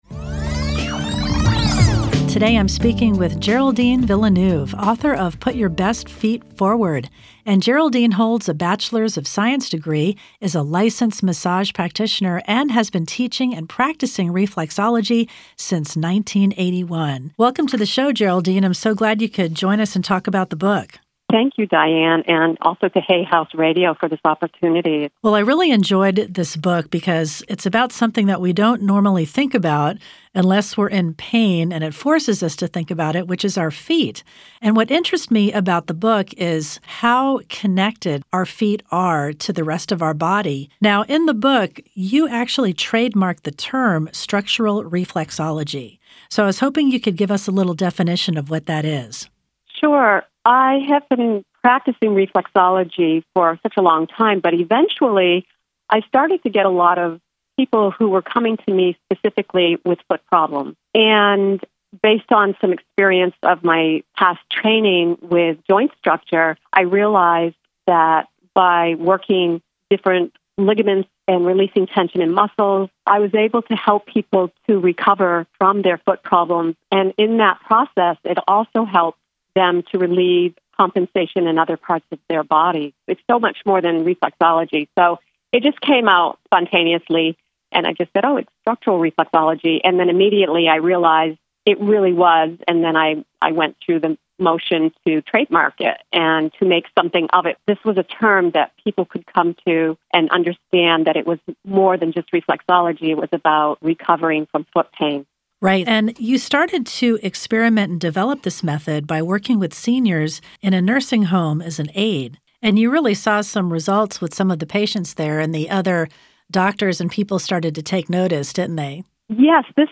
HayHouse radio interview MP3